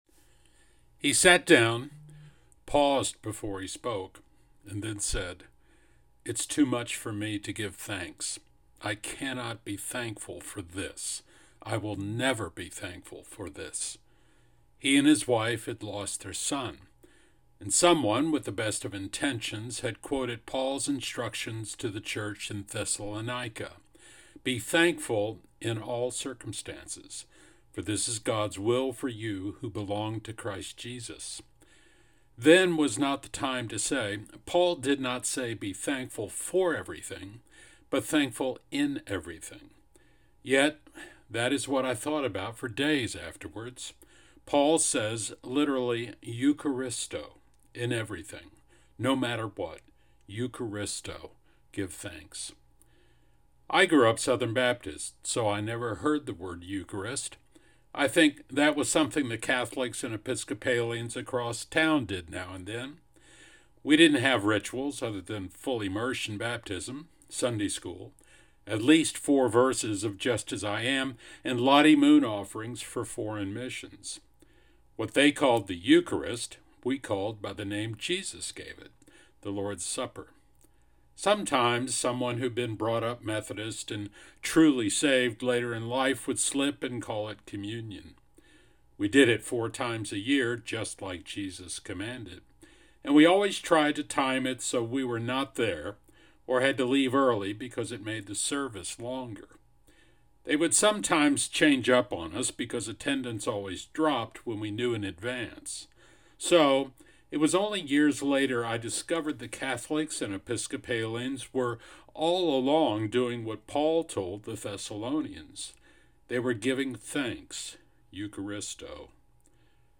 I spoke before Communion at The Gathering conference this year and am sharing it this week.